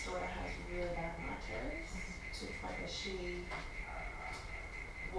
EVP 4 – We are unsure on this one however several of us believe this is a mocking laugh